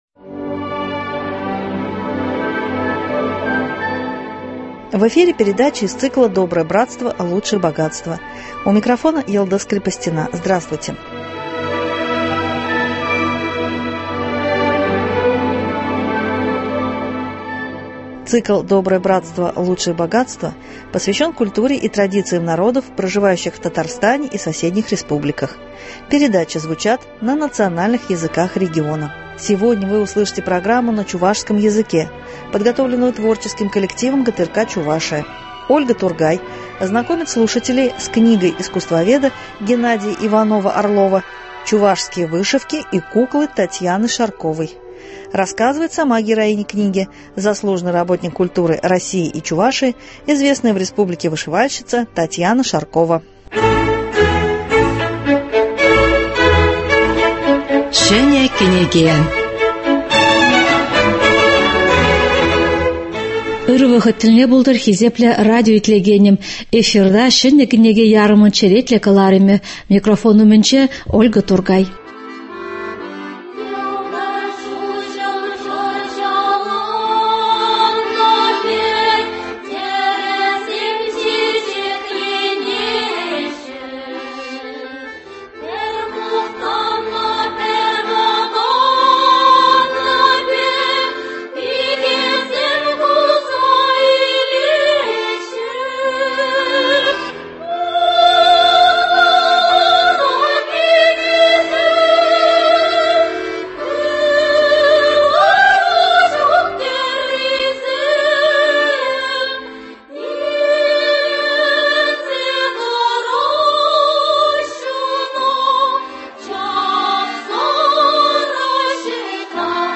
Литературно- музыкальная программа на чувашском языке.